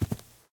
sounds / step / grass5.ogg
grass5.ogg